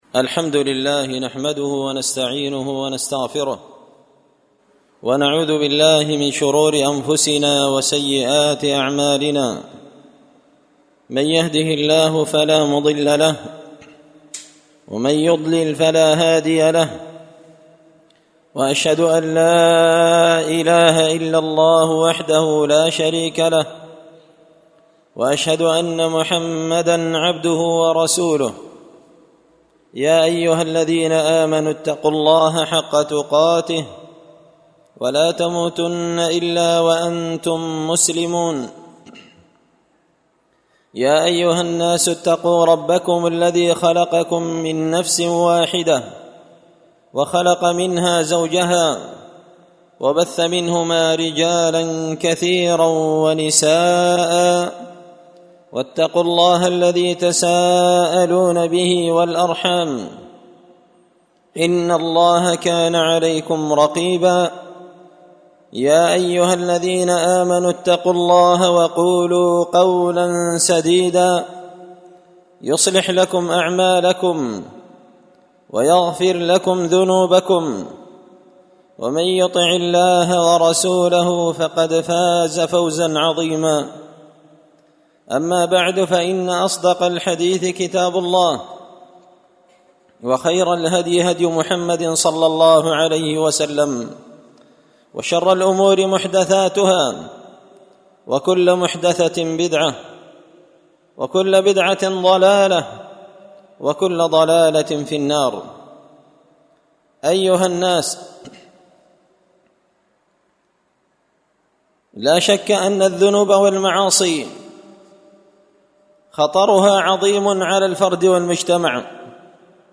خطبة جمعة بعنوان خطر الذنوب والمعاصي على الفرد والمجتمع
دار الحديث بمسجد الفرقان ـ قشن ـ المهرة ـ اليمن